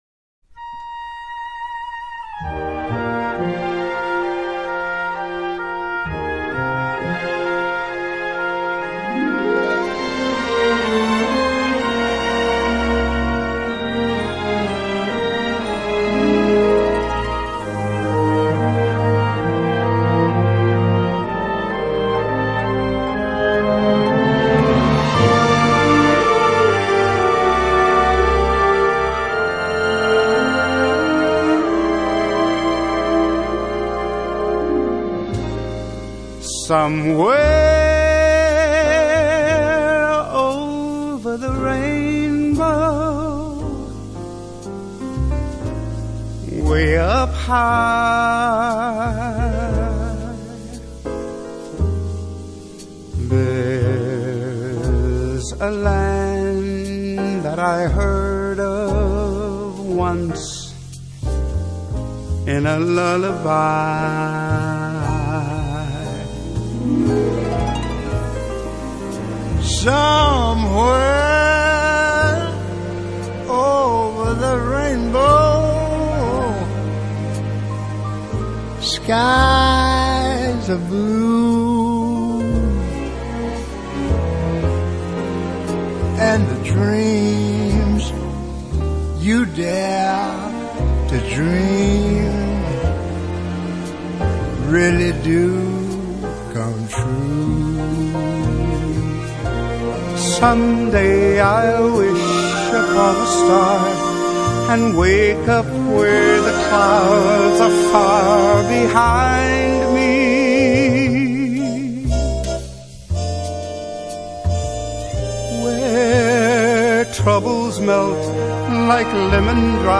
歌喉为此歌带来了安详宁静的感觉，令人情不自禁地沉醉……